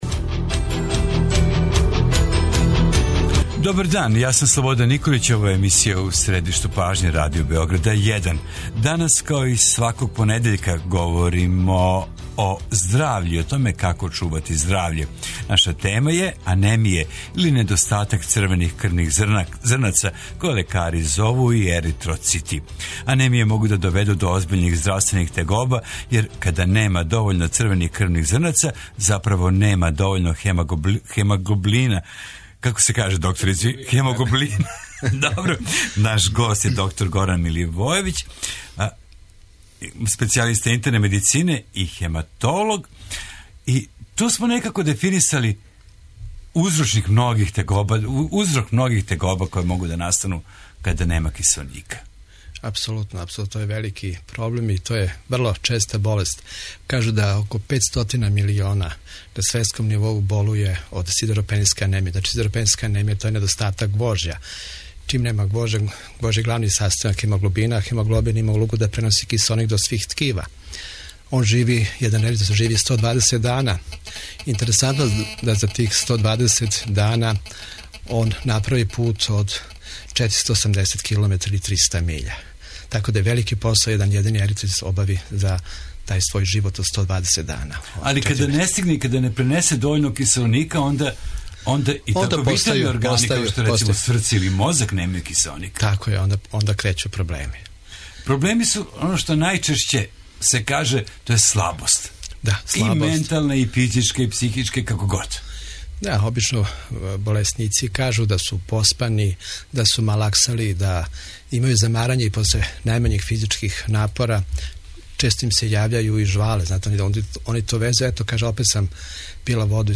Тема здравствене трибине је анемија или недостатак црвених крвних зрнаца (еритроцита). Анемија може да доведе до озбиљних здравствених тегоба, јер мањи број еритроцита заправо значи мање хемоглобина, због чега је смањено достављање кисеоника важним органима као што су мозак, срце или мишићи.